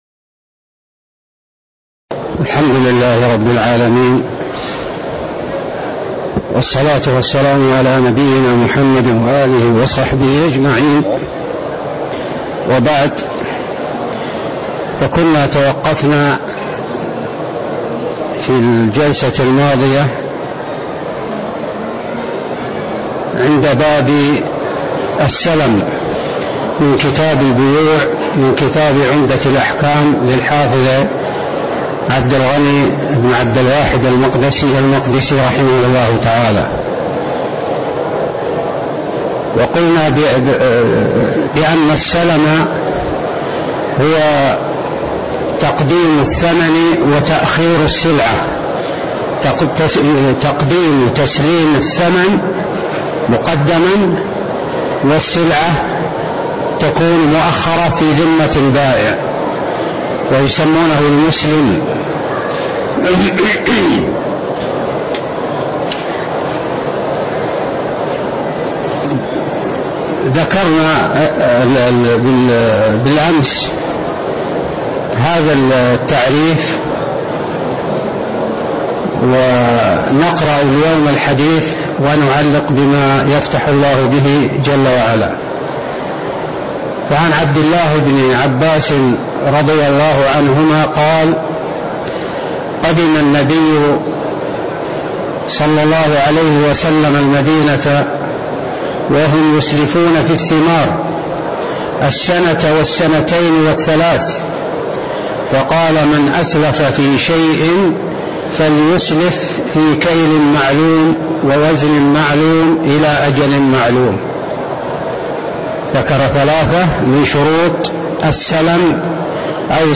تاريخ النشر ٢٨ محرم ١٤٣٧ هـ المكان: المسجد النبوي الشيخ